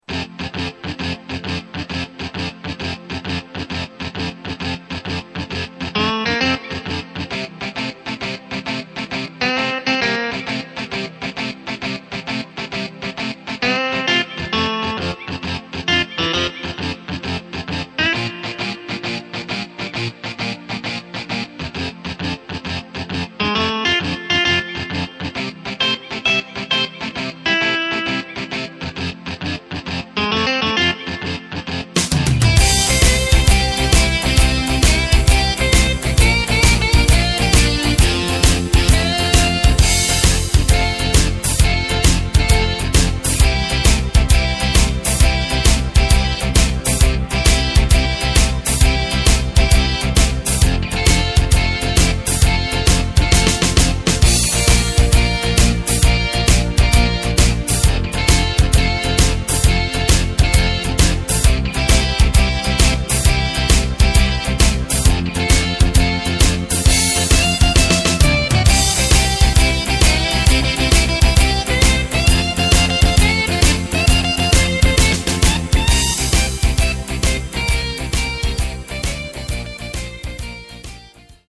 Takt:          4/4
Tempo:         133.00
Tonart:            F
Playback mp3 Demo